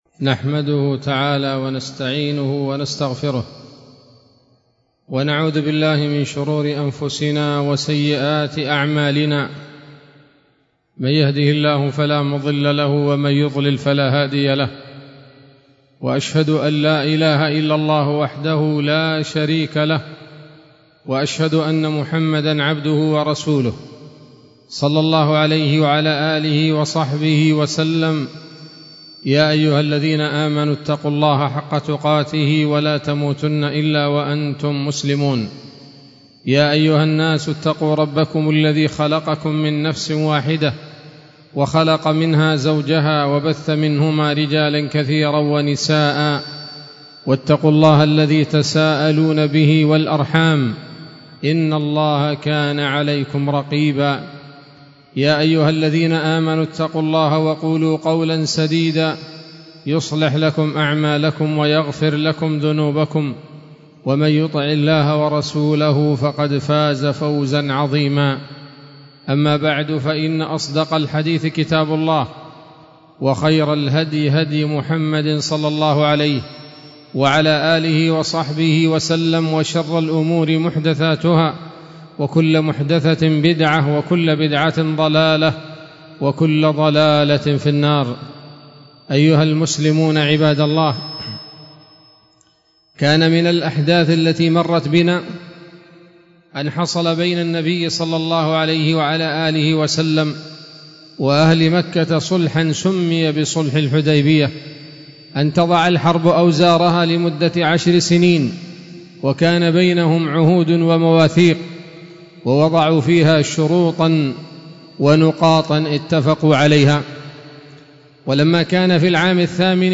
خطبة جمعة بعنوان: (( السيرة النبوية [29] )) 5 صفر 1446 هـ، دار الحديث السلفية بصلاح الدين